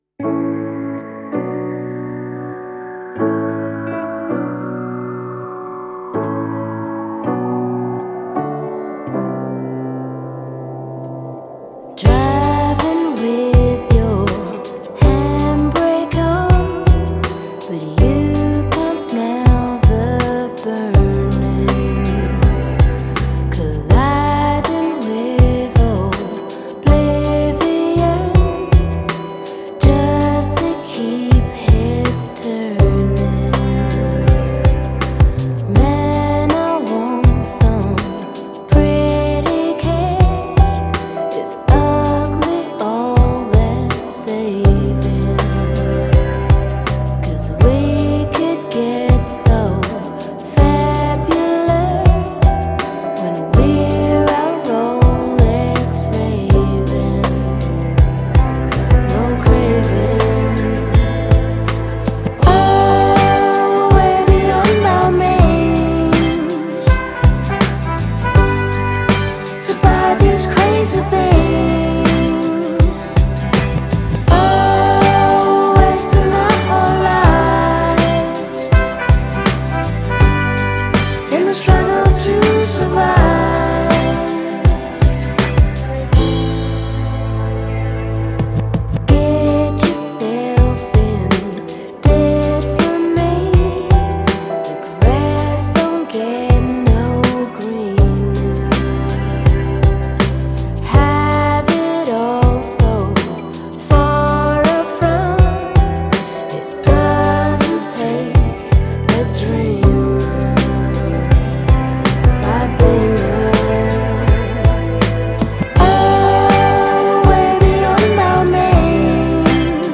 Like a lullaby for stressed-out adults.